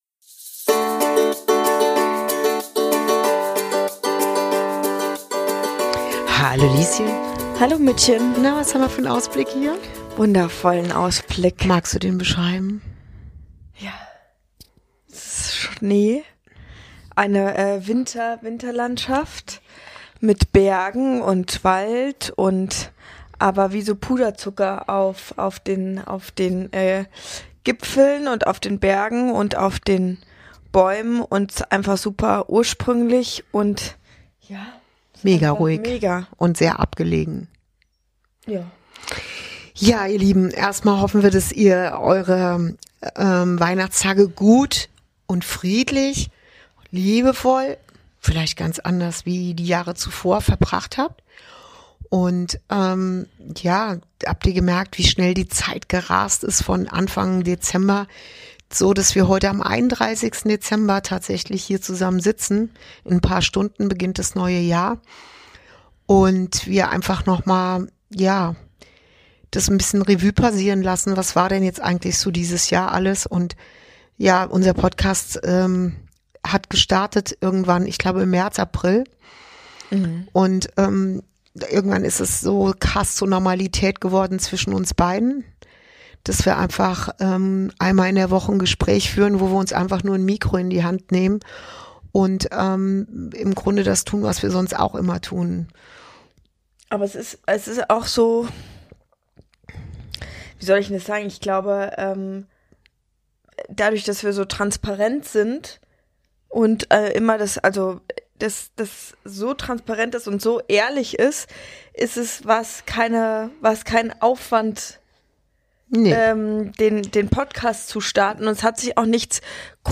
Folge 46: Für mehr Bewusstsein, Gleichgewicht und Frieden ~ Inside Out - Ein Gespräch zwischen Mutter und Tochter Podcast